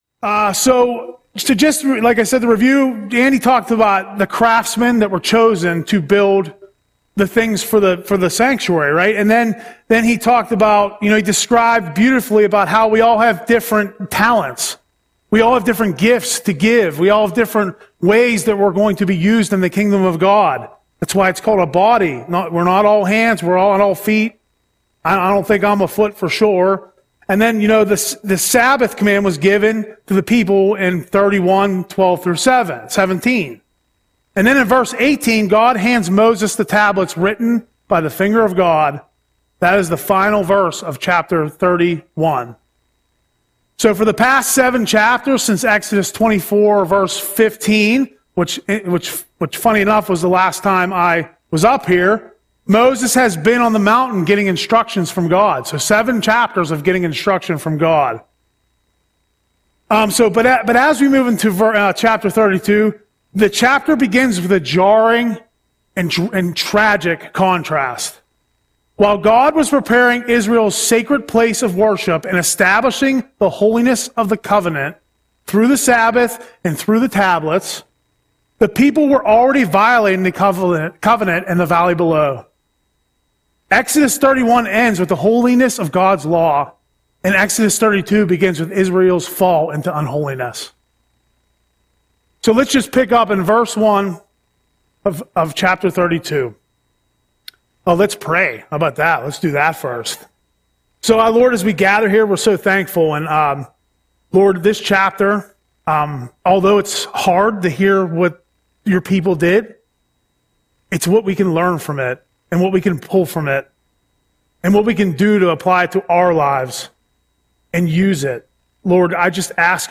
Audio Sermon - June 25, 2025